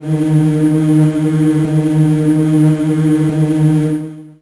55be-syn08-d#2.aif